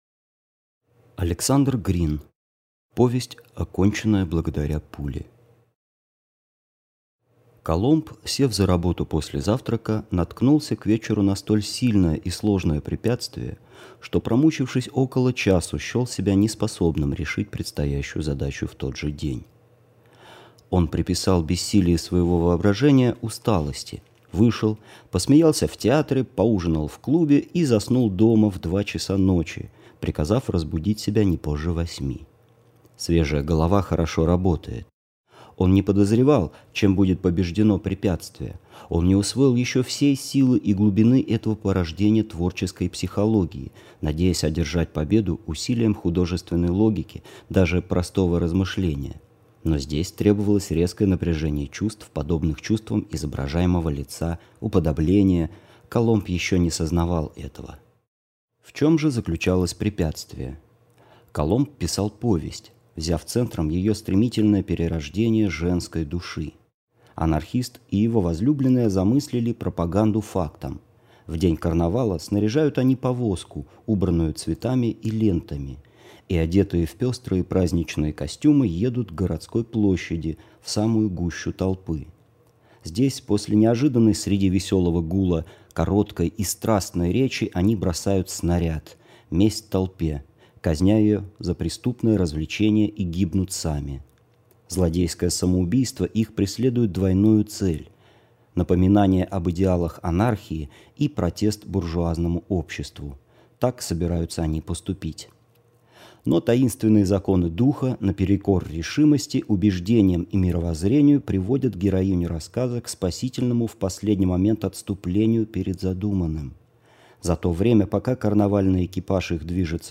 Аудиокнига Повесть, оконченная благодаря пуле | Библиотека аудиокниг